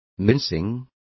Complete with pronunciation of the translation of mincing.